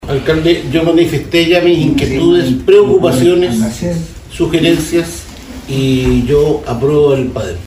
En tanto, el Concejal Jorge Luis Bórquez se manifestó a favor de la aprobación: